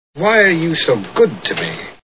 North by Northwest Movie Sound Bites